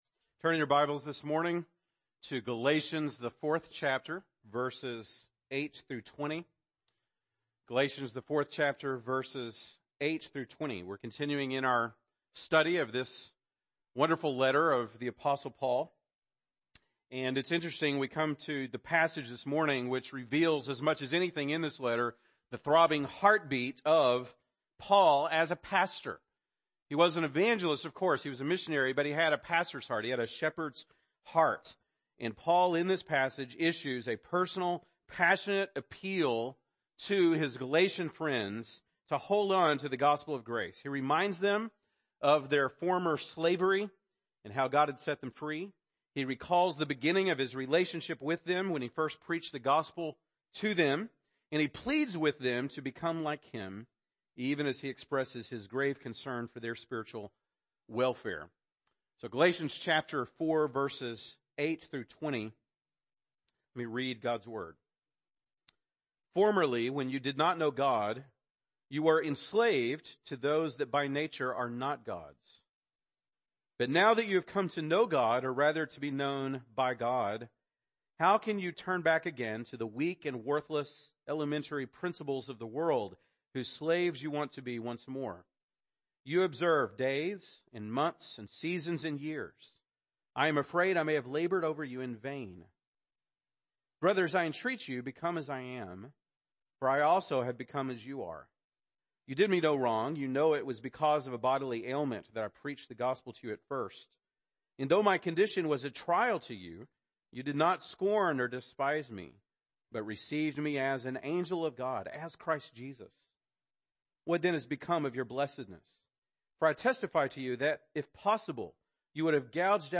November 5, 2017 (Sunday Morning)